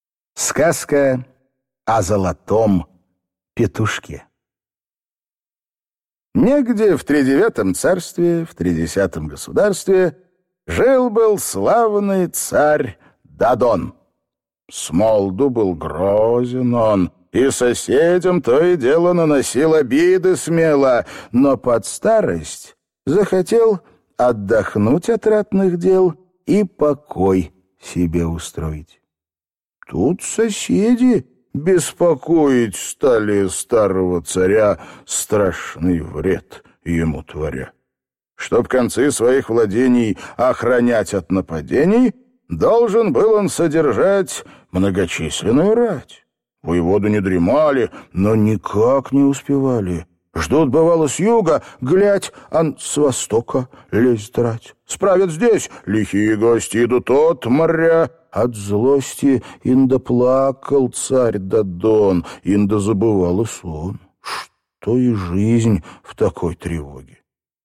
Аудиокнига Сказка о золотом петушке | Библиотека аудиокниг